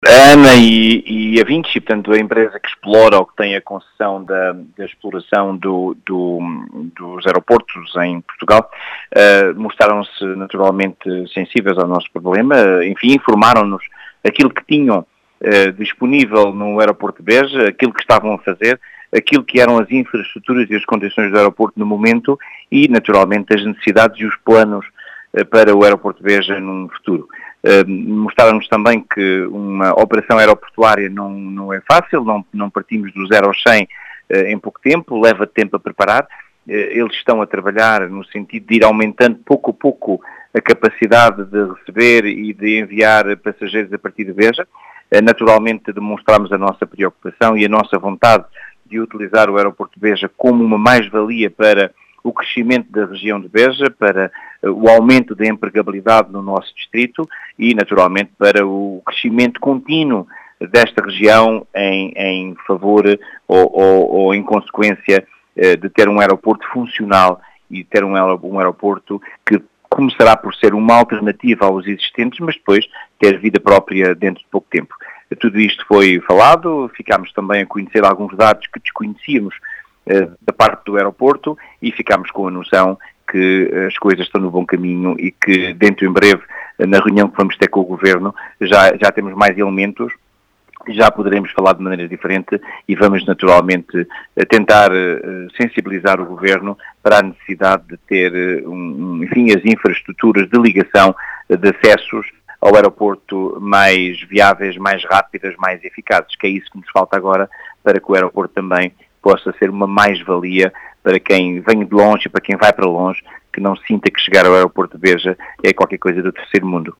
As explicações são do presidente da Comunidade Intermunicipal do Baixo Alentejo, António Bota, que afirma que o Aeroporto de Beja está “no bom caminho”.